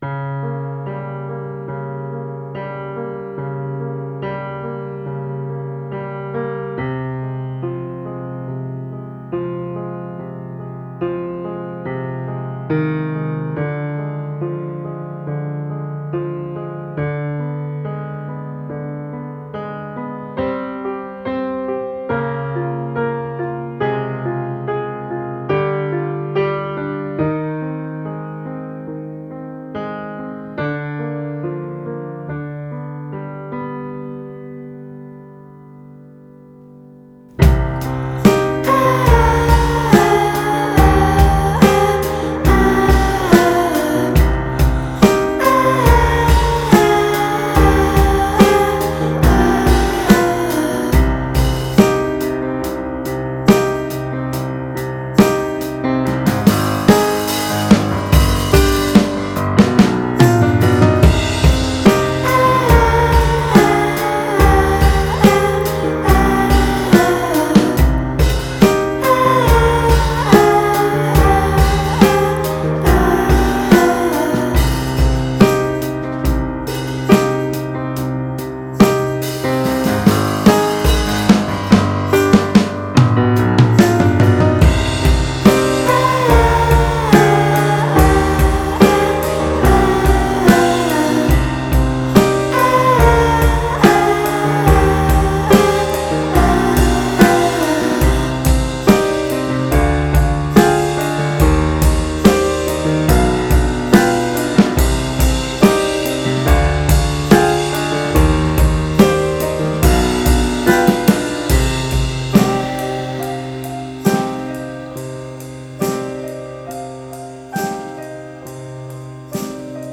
موزیک خارجی بی کلام